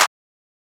Metro High Clap.wav